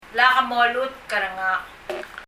発音
molutk er ngak が [mɔlutkərəŋa] と聞こえます。
molutk の k が　er とリエゾンして音としてよみがえってます。
ngak の k は、文末なので、息音になってきこえません。